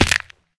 fallbig2.ogg